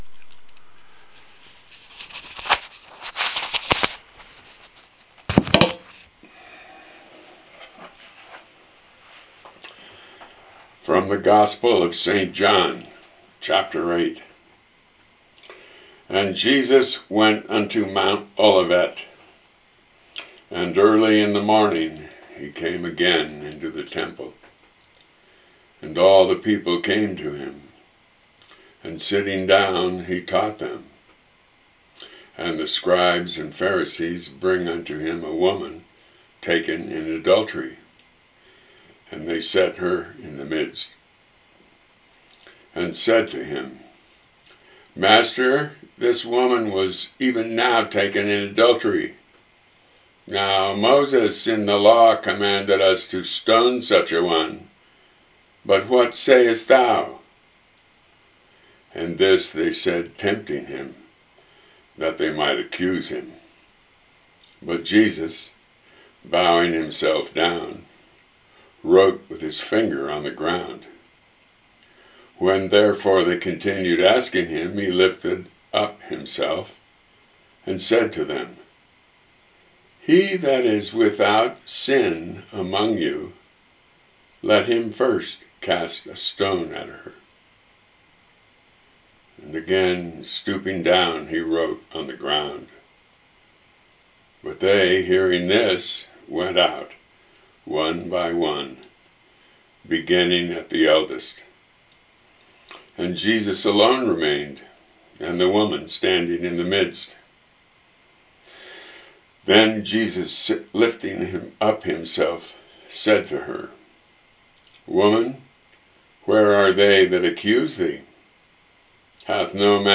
I will also be reading the words of Holy Scripture, Church Saints, Church Doctors & other texts, which can inspire and help one recollect the power and peace of The Holy Trinity, as a great help in continuing on the path of Christian Perfection.